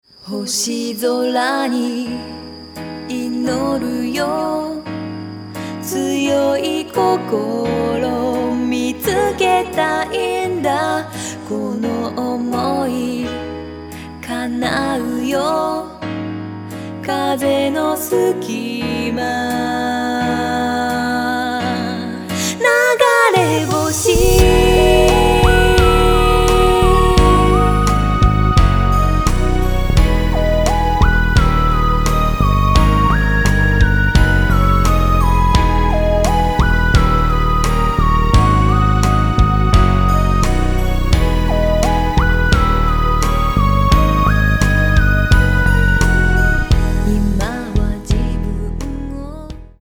■All music composition , wards , arrengement & guitar play
■Vocal